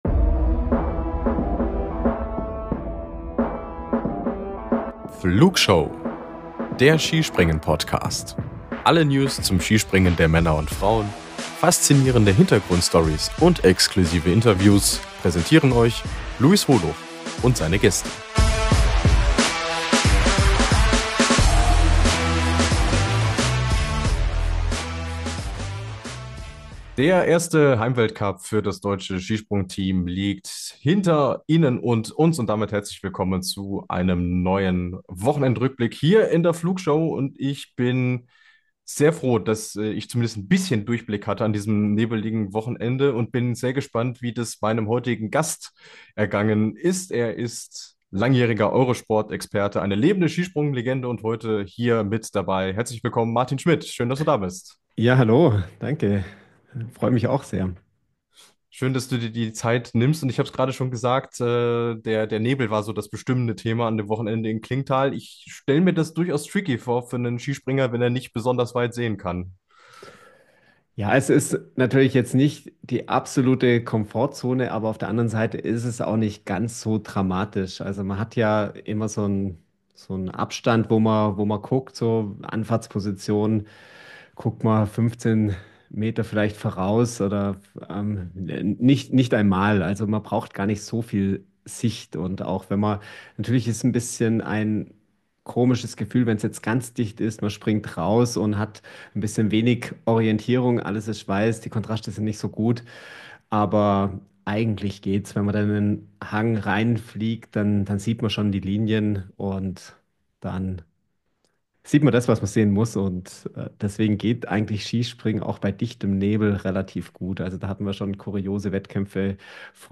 mit Eurosport-Experte Martin Schmitt wieder viel zu besprechen: Warum ist Domen Prevc gerade so gut? Warum ist mit Andreas Wellinger nun der nächste hochdekorierte DSV-Skispringer in der Formkrise? Und wie nah sind die deutschen Frauen am Podestplatz dran?